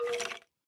骷髅：咯咯声
空闲时随机播放这些音效
Minecraft_Skeleton_say1.mp3